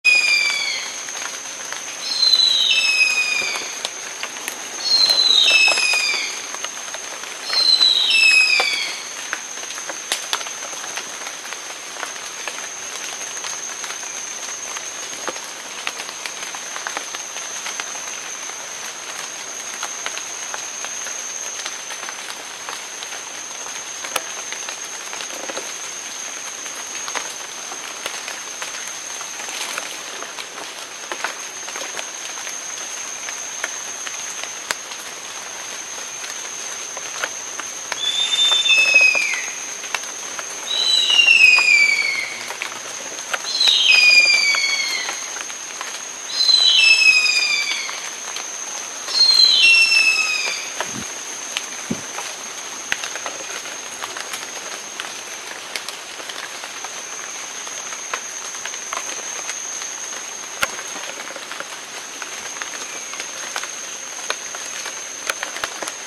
1. Harpy eagles (Harpia harpyja) audio recordings from WikiAves: